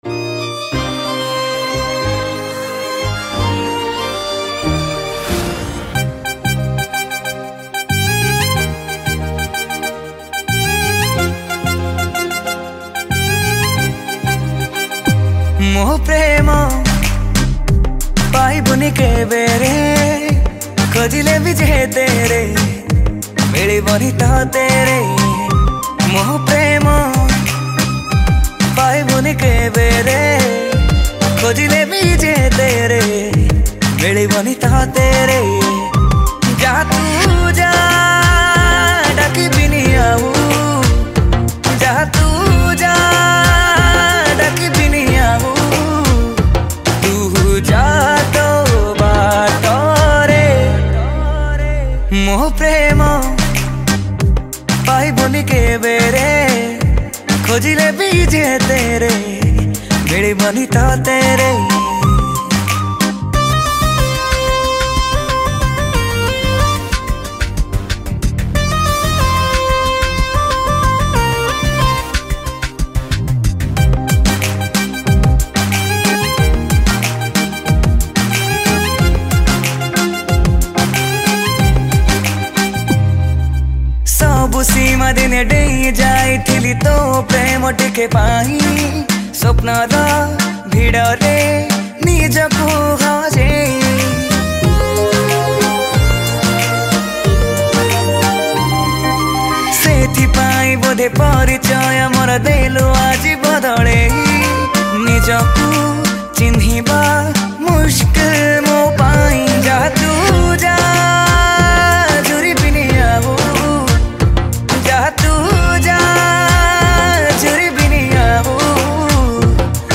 Odia Sad Song